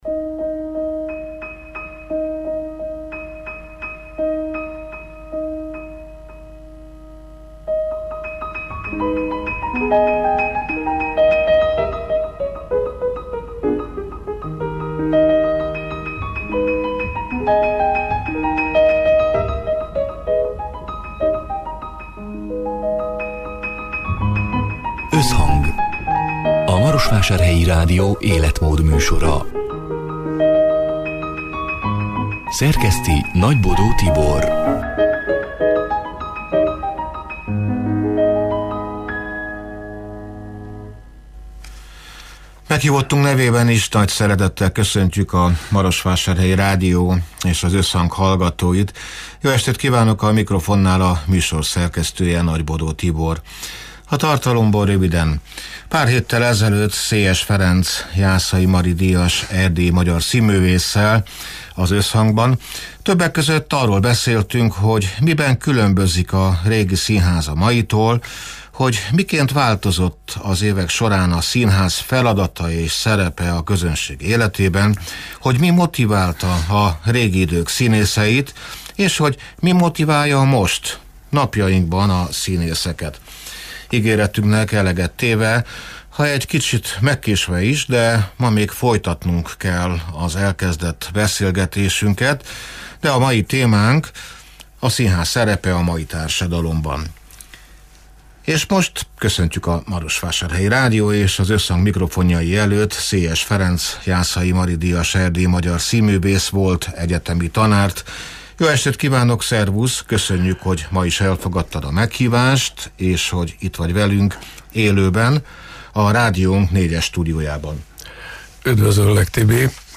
(elhangzott: 2024. december 18-án, szerdán délután hat órától élőben)